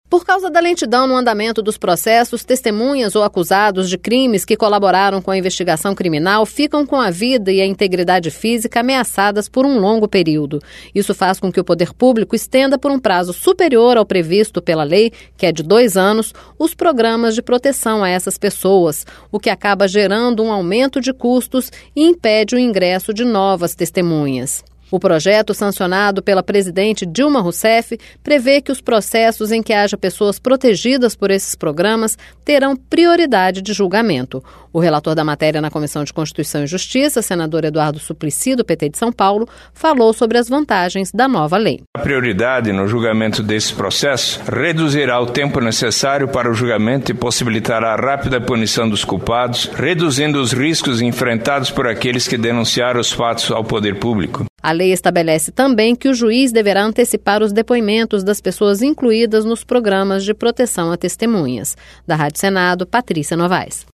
O projeto aprovado na última terça-feira prevê que os processos em que haja pessoas protegidas por esses programas terão prioridade de julgamento. O relator da matéria na comissão de Constituição e Justiça, senador Eduardo Suplicy, do PT de São Paulo Suplicy falou sobre as vantagens da nova lei.